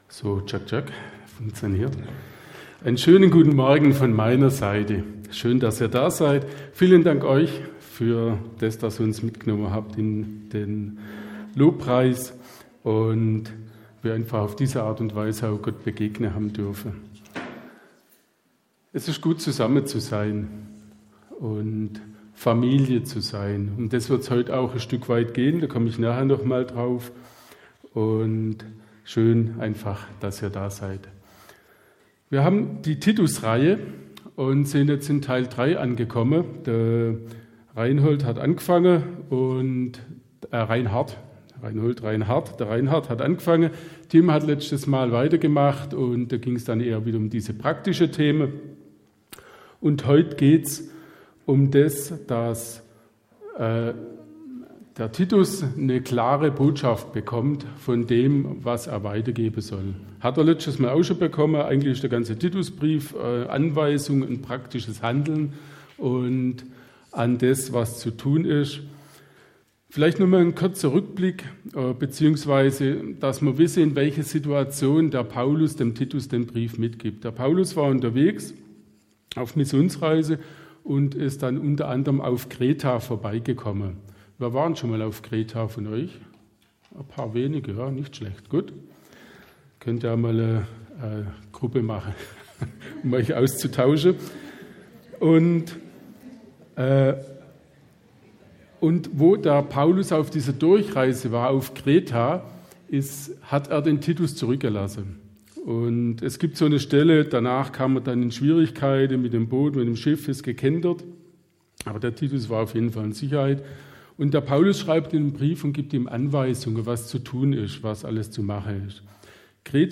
Predigt 18.08.2024 - SV Langenau